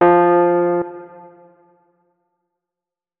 electric_piano
notes-29.ogg